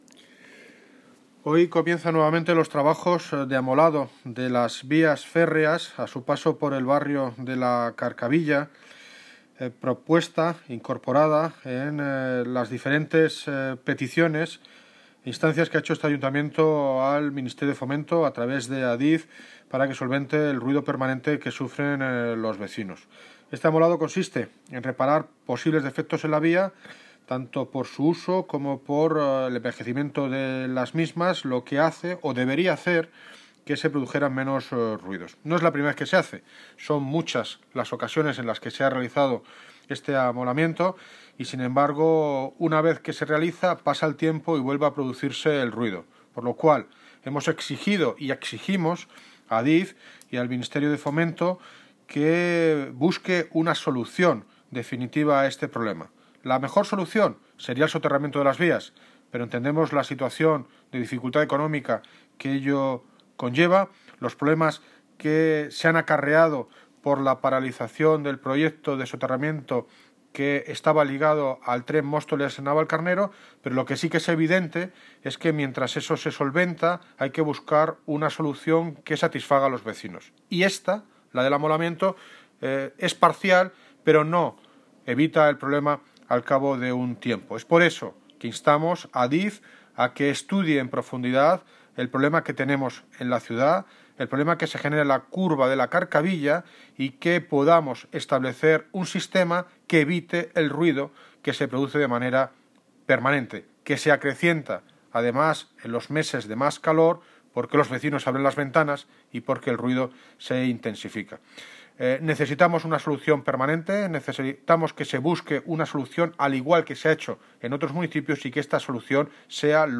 Audio - David Lucas (Alcalde de Móstoles) exige a Ministerio de Fomento que acabe con los ruidos de trenes